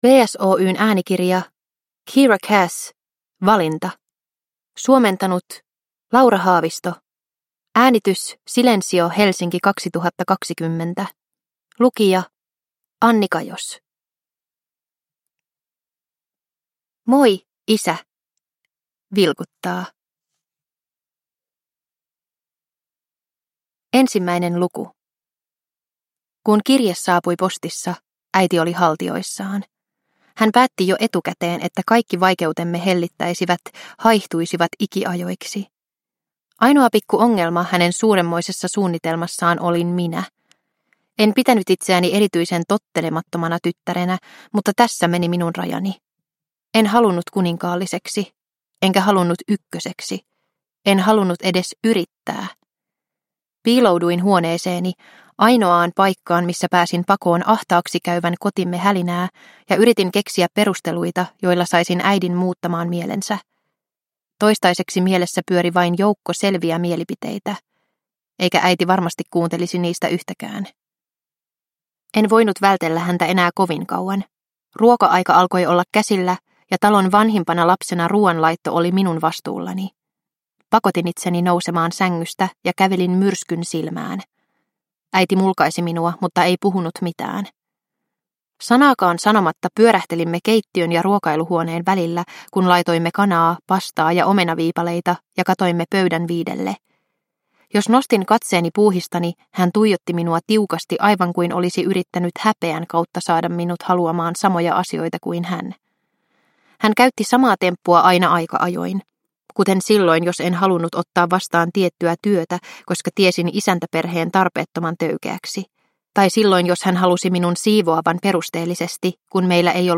Valinta – Ljudbok – Laddas ner